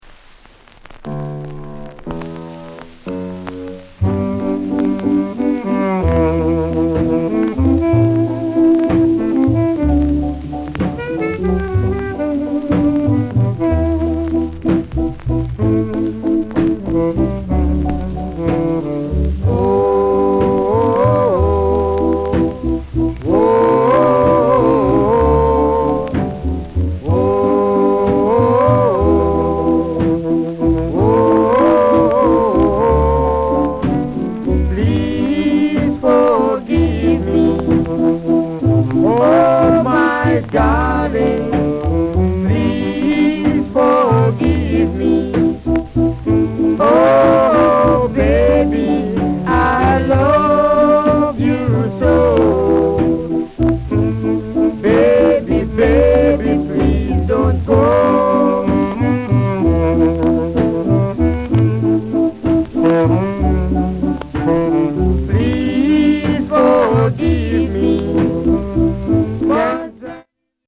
NICE SHUFFLE